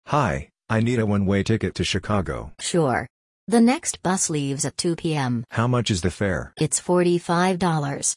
💬 Interactive Sample Conversations